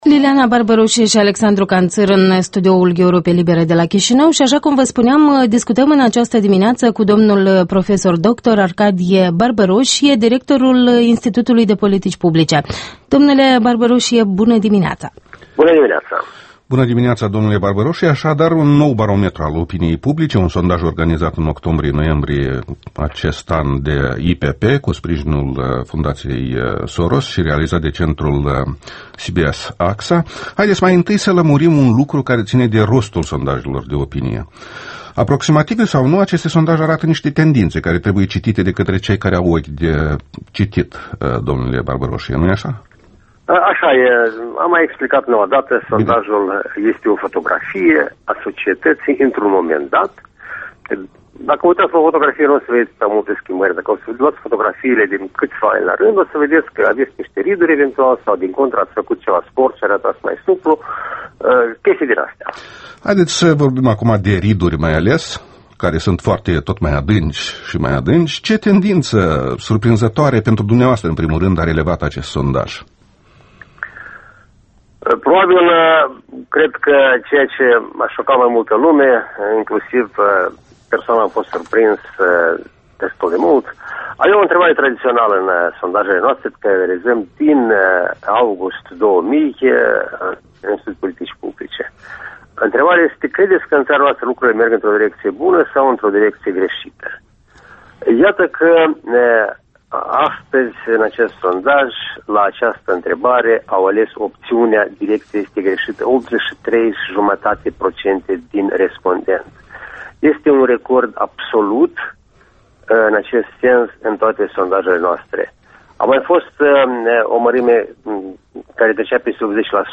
Interviul matinal al Europei Libere